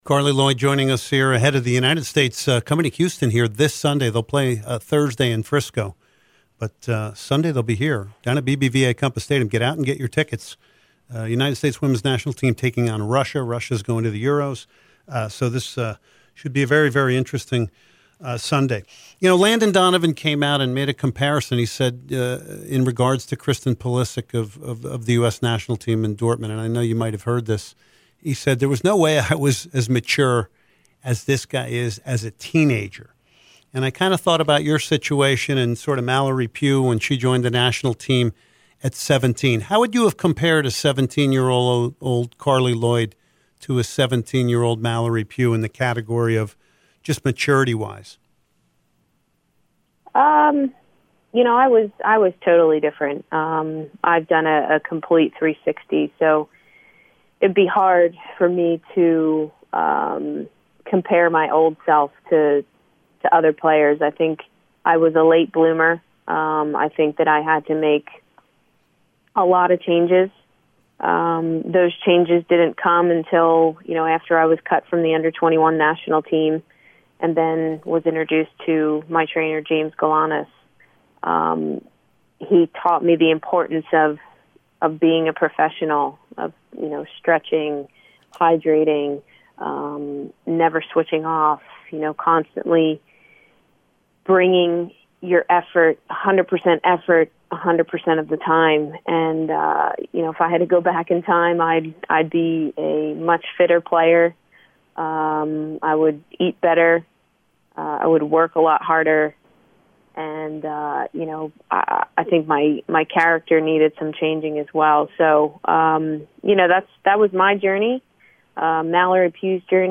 04/12/2017 BONUS INTERVIEW with Carli Lloyd